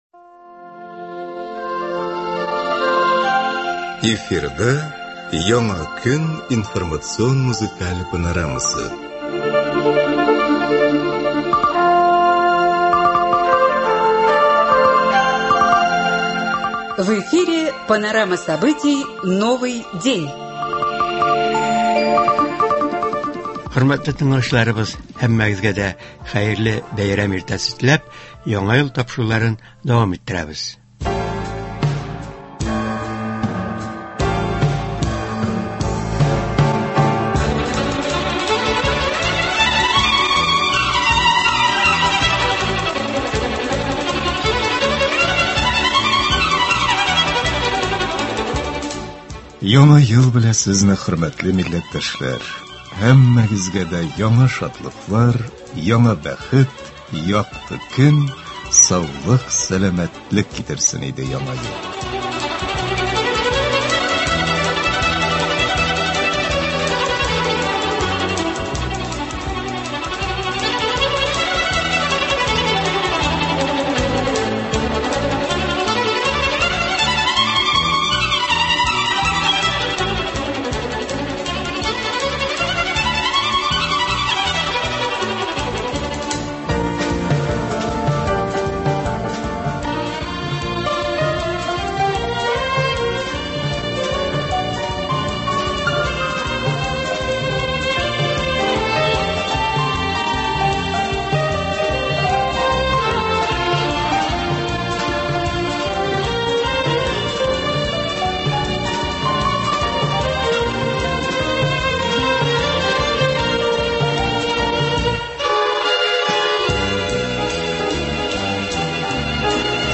“Исәнме, Яңа ел!” Әдәби-музыкаль композиция.
Яңа – 2024 нче елның беренче иртәсендә тыңлаучыларыбыз игътибарына “Исәнме, Яңа ел!” дигән информацион-музыкаль тапшыру тәкъдим ителә.